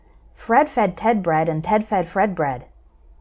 I’m going to say each one slowly, and then try to say it fast – and I hope you’ll try repeating after me.
Slow